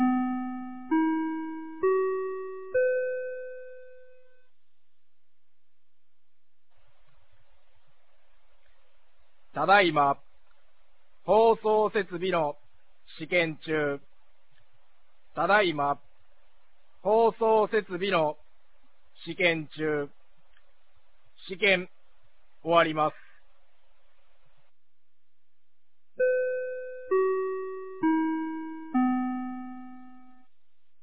2026年01月17日 16時06分に、美浜町より全地区へ放送がありました。